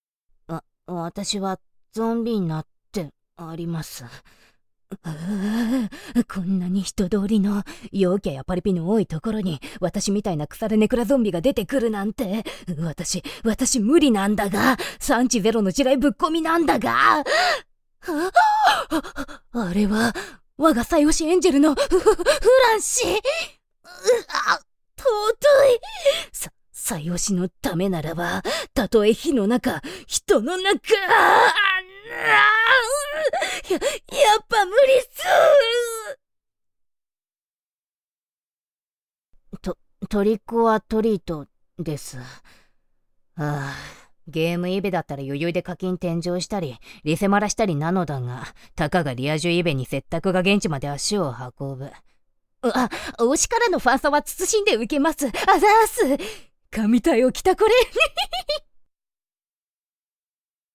ゾンビの女の子
いつもおどおどしてて恥ずかしがり屋さんでも大好きなものには一直線